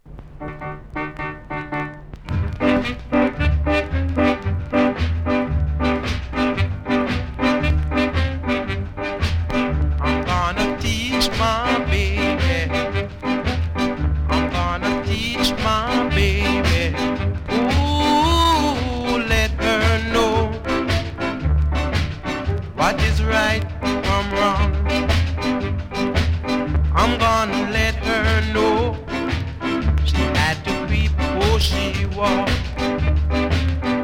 うすキズそこそこありますが音には影響せず良好です。